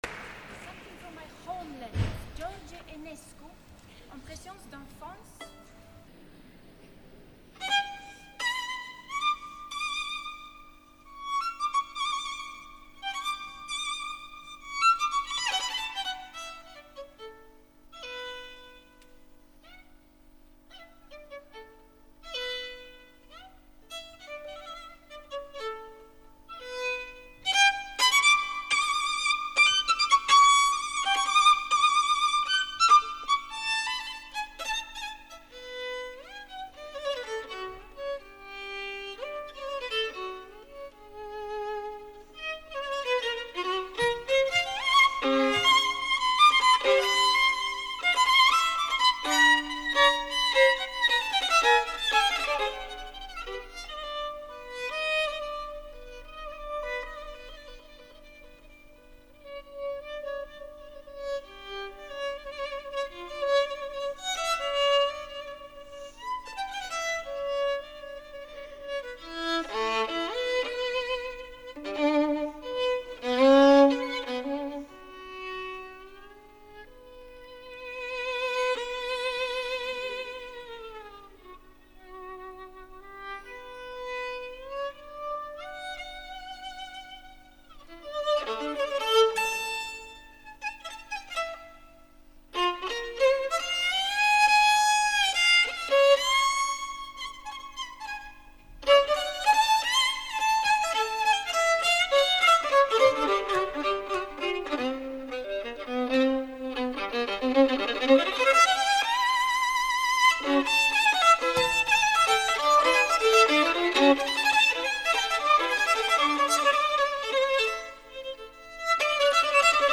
L’Auditori de Barcelona diumenge 27 de febrer de 2011